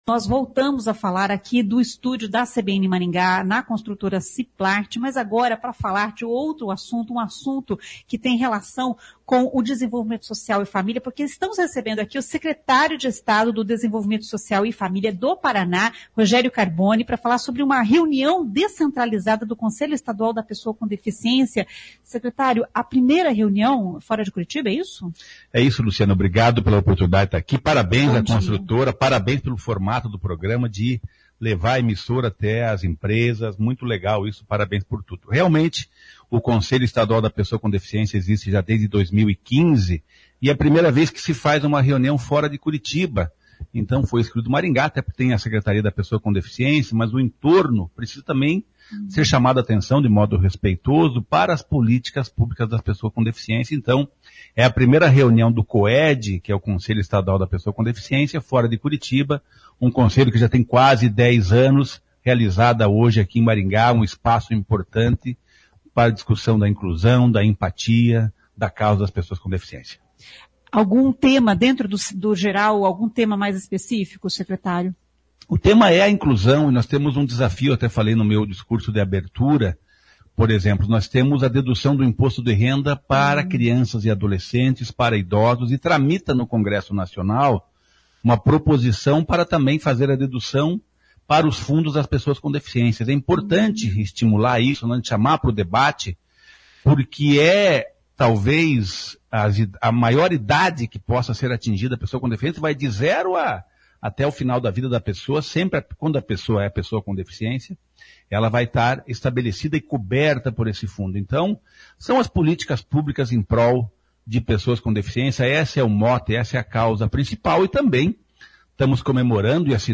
Em entrevista à CBN, no estúdio montado na construtora Ciplart, o secretário falou sobre o edital de R$ 100 mi para entidades que atendem crianças e adolescentes.